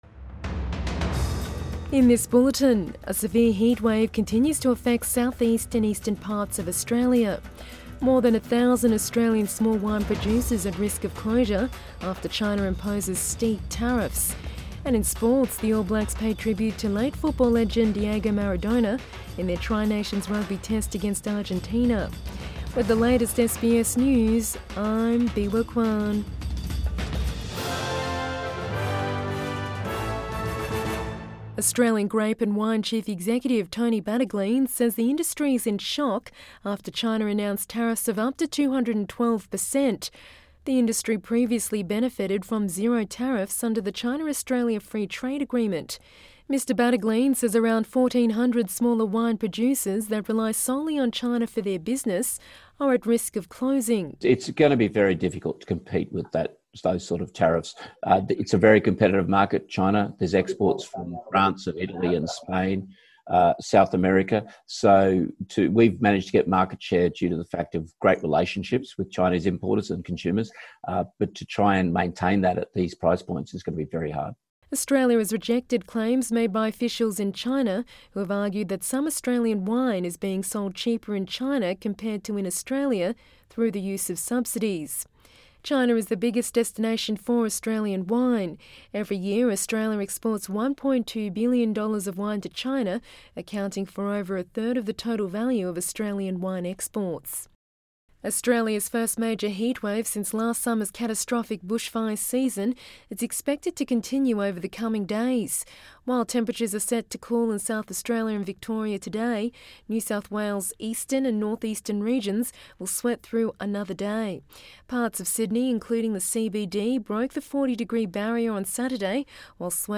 AM bulletin 29 November 2020